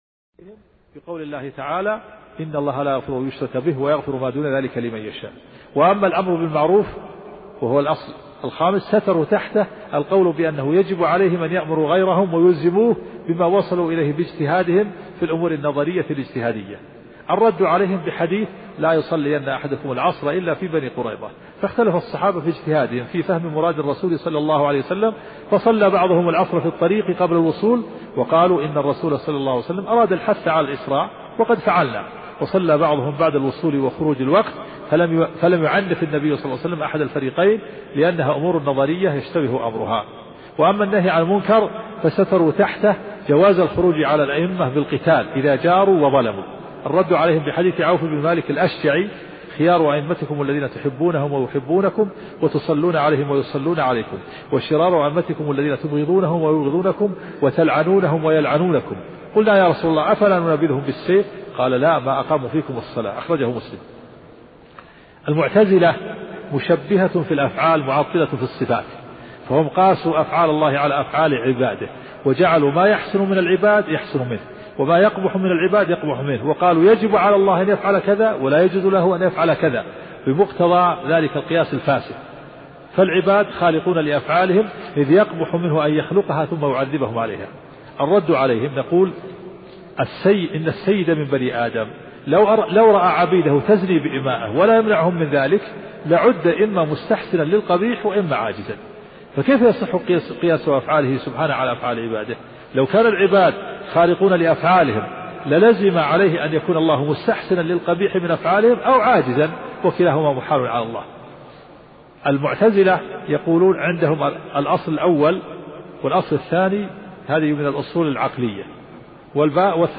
الدرس 20